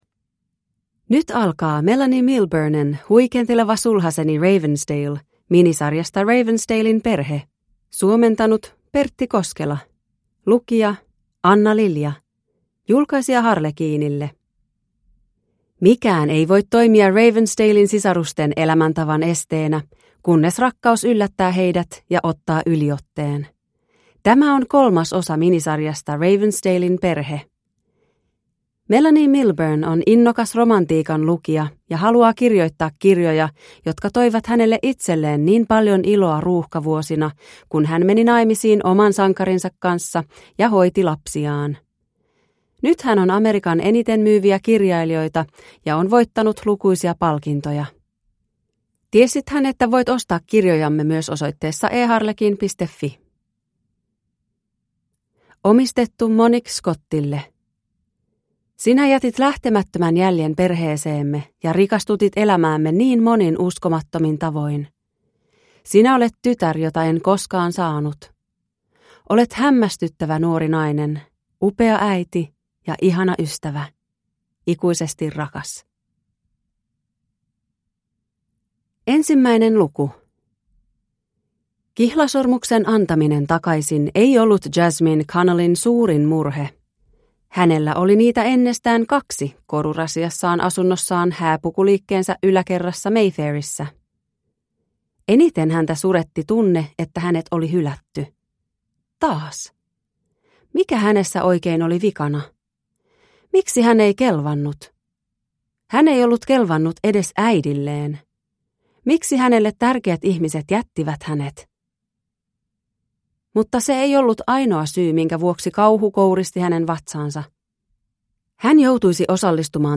Huikenteleva sulhaseni Ravensdale (ljudbok) av Melanie Milburne